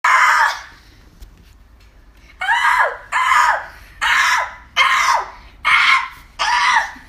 Seagull  1.wav